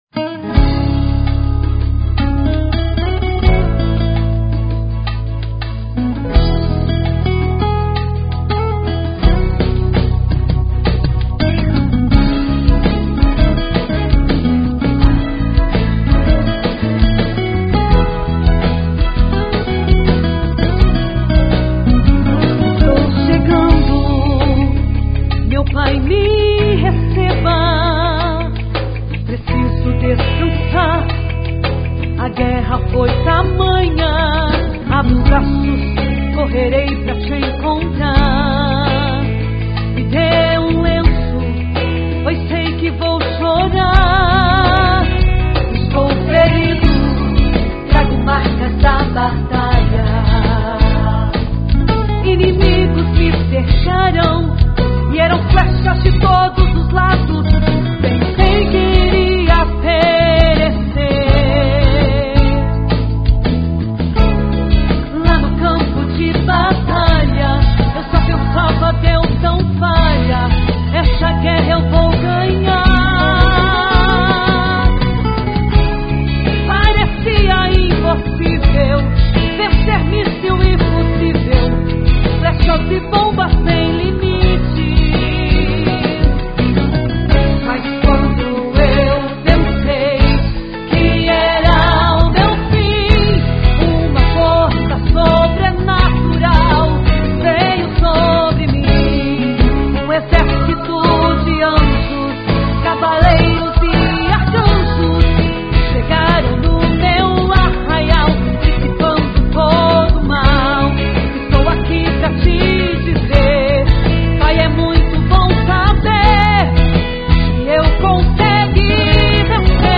gospel.